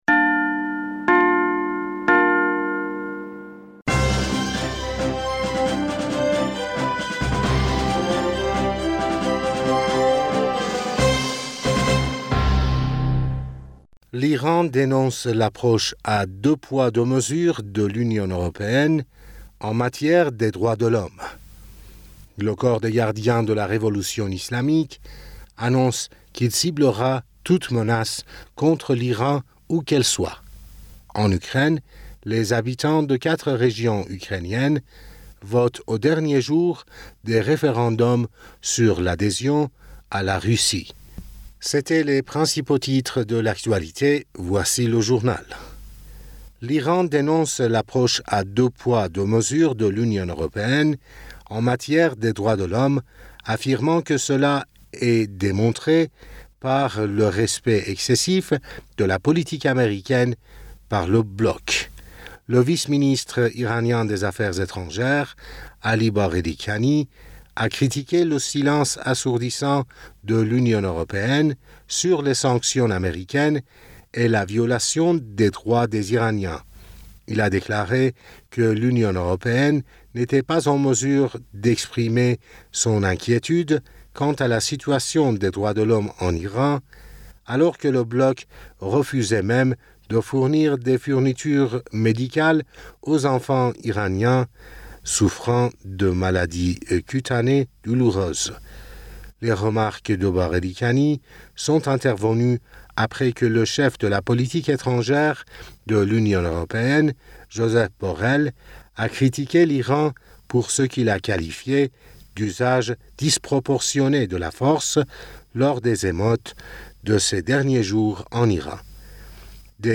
Bulletin d'information Du 27 Septembre